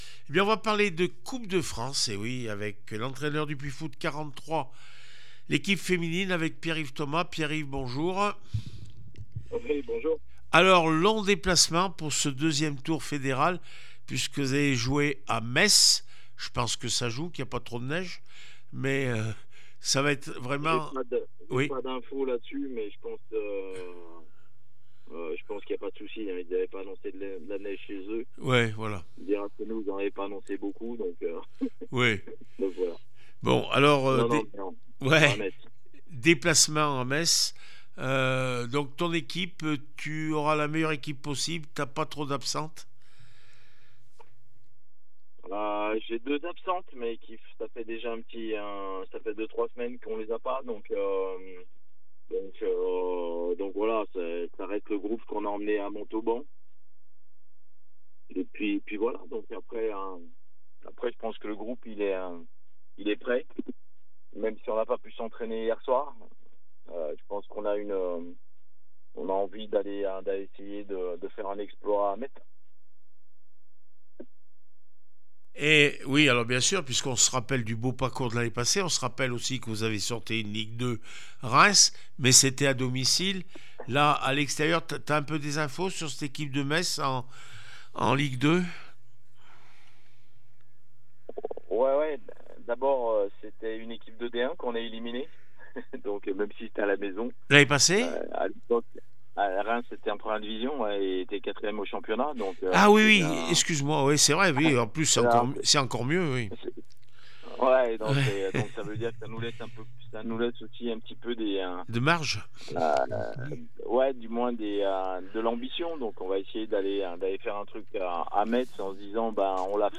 14 décembre 2024   1 - Sport, 1 - Vos interviews